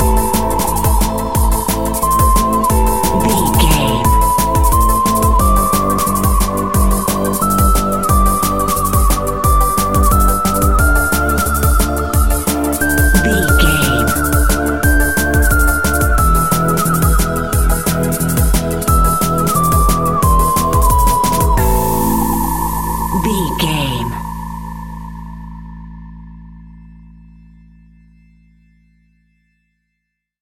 Aeolian/Minor
Fast
groovy
uplifting
futuristic
driving
energetic
drum machine
synthesiser
organ
sub bass
synth leads
synth bass